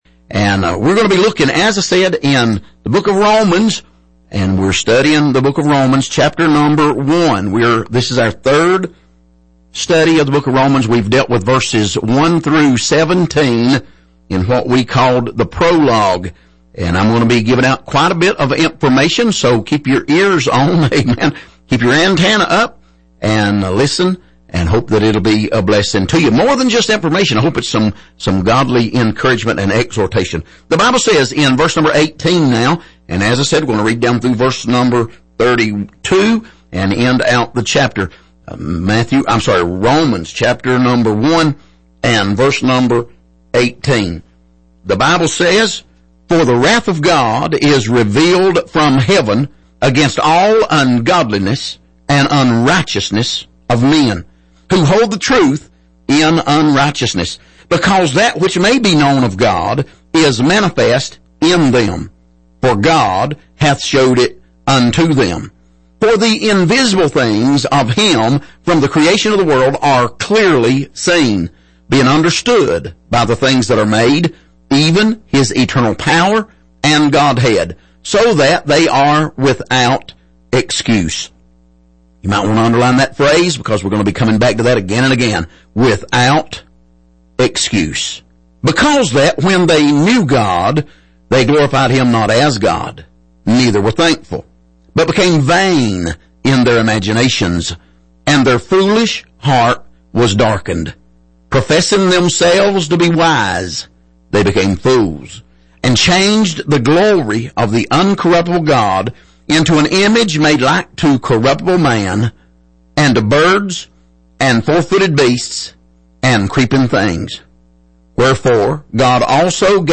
Passage: Romans 1:18-32 Service: Sunday Morning